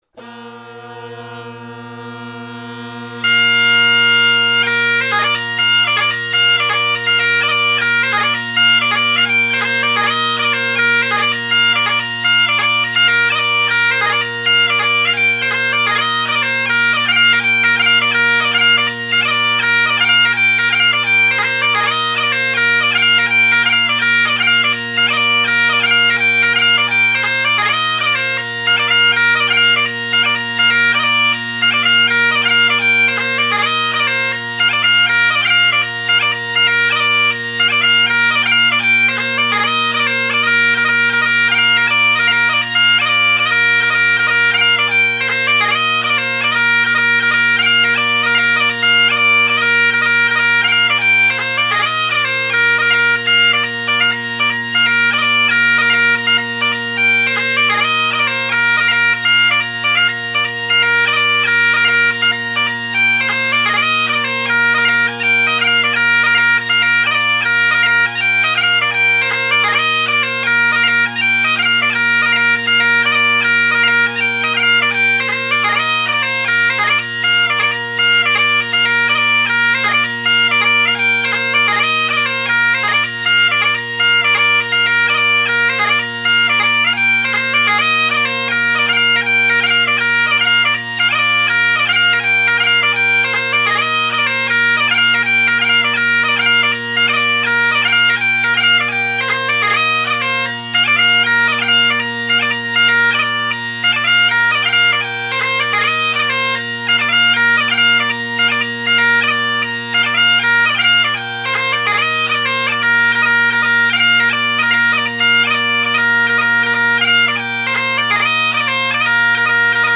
Below are several recordings made over several days of most of my collection of bagpipes.
Jacky Latin (David Glen + original Kinnaird, Colin Kyo + Husk) – mic off to the left (recorded 2019-08-16)